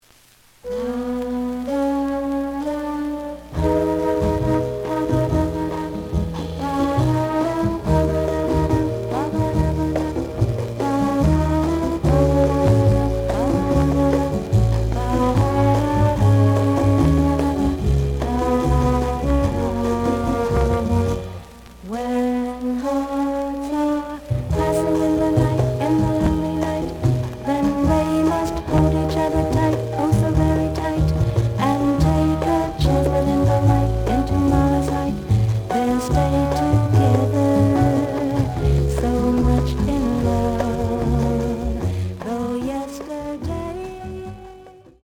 The audio sample is recorded from the actual item.
●Genre: Soul, 60's Soul
Looks good, but slight noise on both sides.